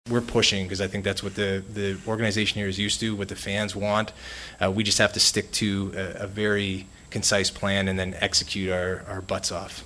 Penguins president of hockey operations Kyle Dubas took questions for over half an hour yesterday in his season-ending news conference, and while he is certain he has the team on the right track, he hinted it might be another year before fans see the sort of Penguins Stanley Cup-contending team they are used to.